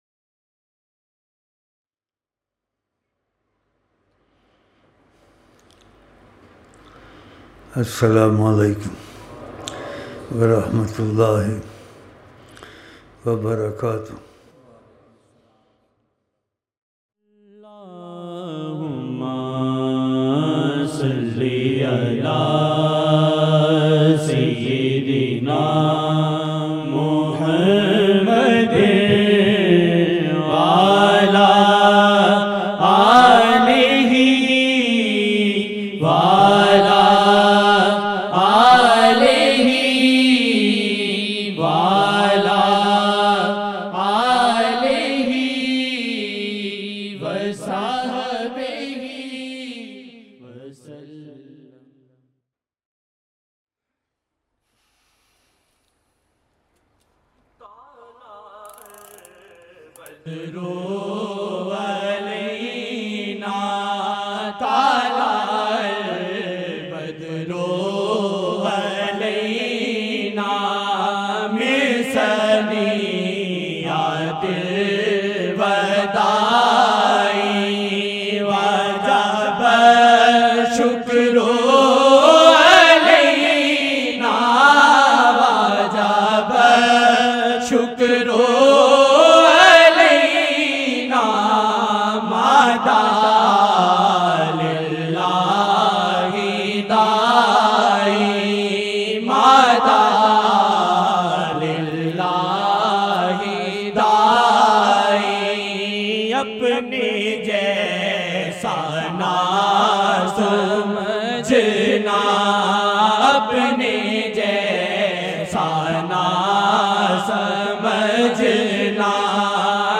22-June-2012-01-Shaban-1433-Esha-Zaruri-Taleem-Shareef-Part-1 VM-0638-P1 22 Jun 2012 New/Daily Taleem Naat Shareef